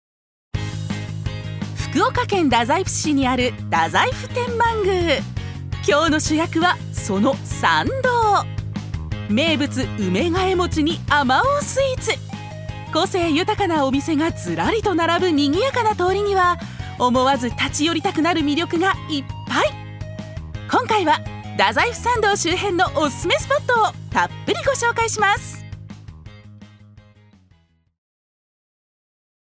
ナレーション３